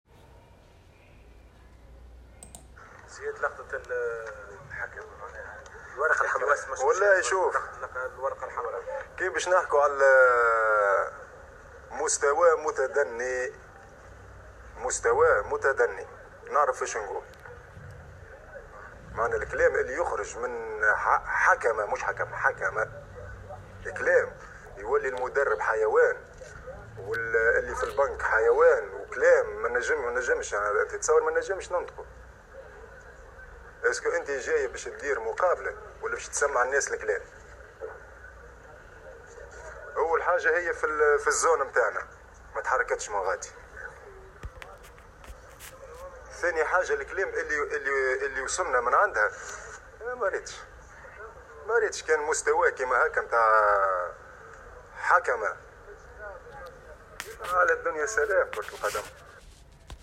تصريح إعلامي